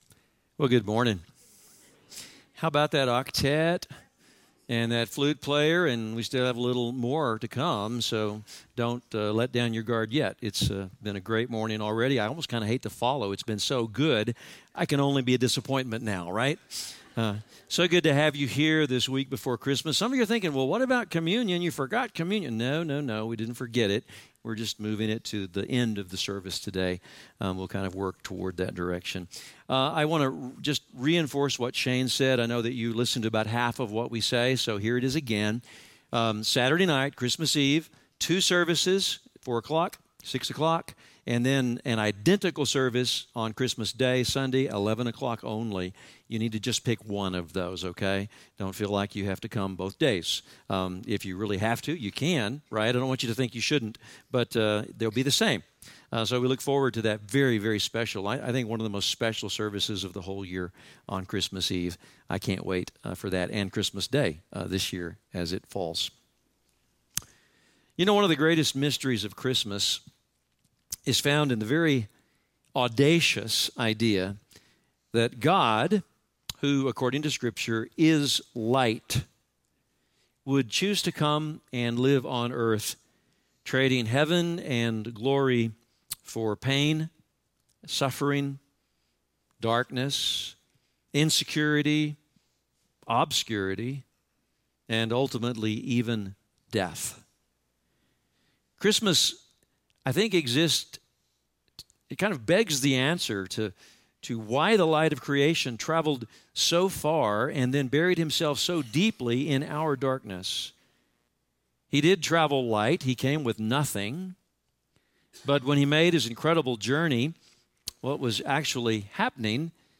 In our message time together on this fourth Sunday of Advent, we will explore two angles that may help answer why. One, Christmas makes God real to us. Two, Christmas makes God close to us.